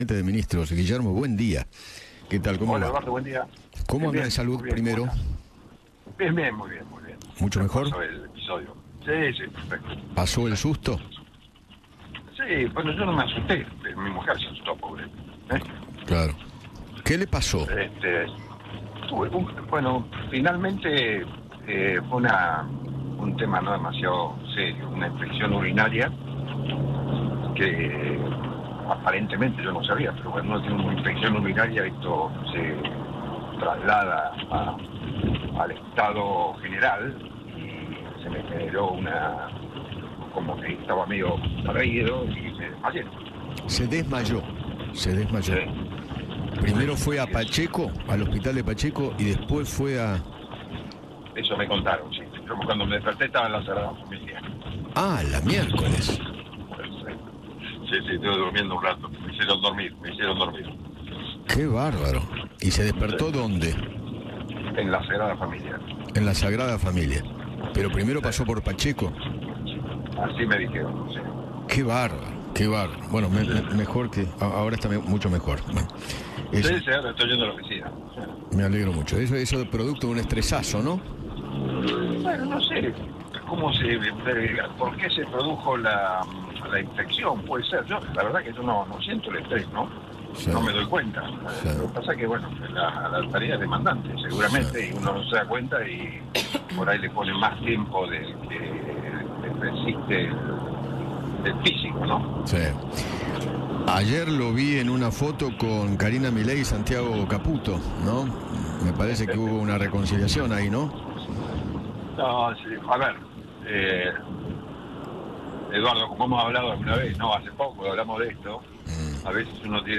Guillermo Francos, Jefe de Gabinete, habló con Eduardo Feinmann sobre el asado de Javier Milei junto a lo diputados nacionales que votaron a favor de su veto a la reforma jubilatoria y se refirió a su relación con Santiago Caputo.